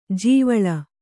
♪ jīvaḷa